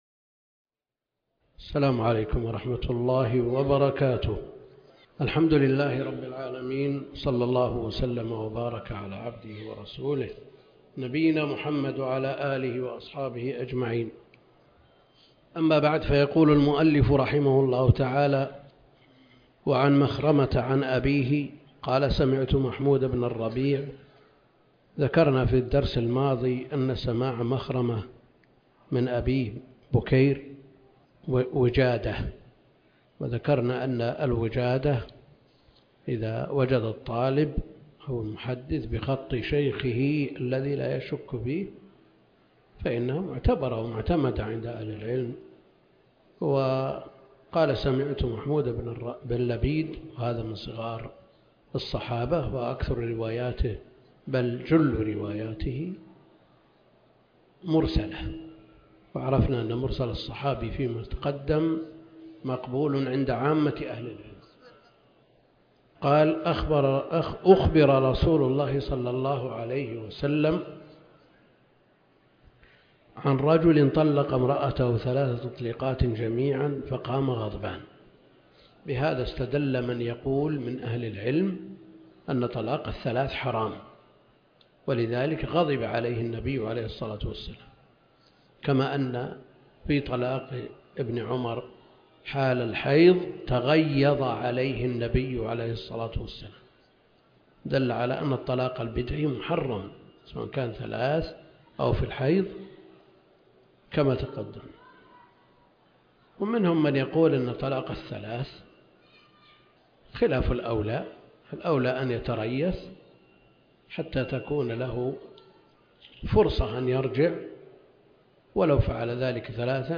الدرس (3) كتاب الطلاق من المحرر في الحديث - الدكتور عبد الكريم الخضير